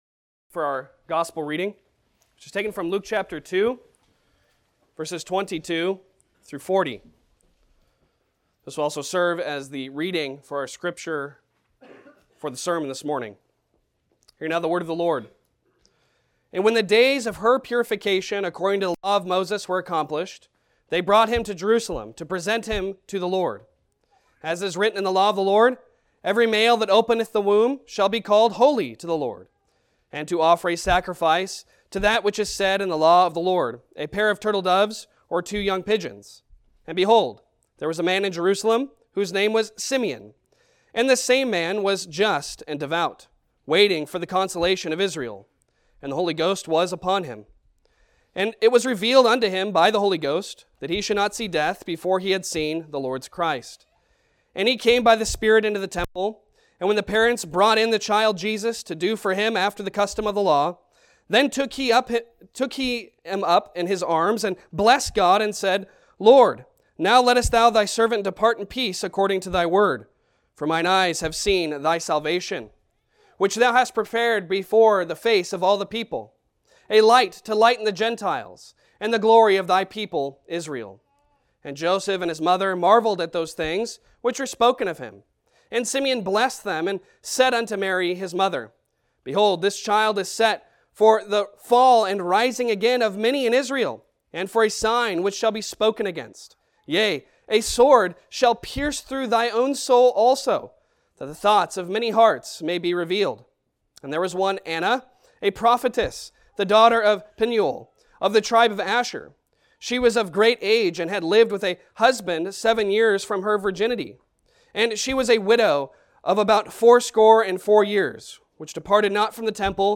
Passage: Luke 2:25-35 Service Type: Sunday Sermon Download Files Bulletin « Reformation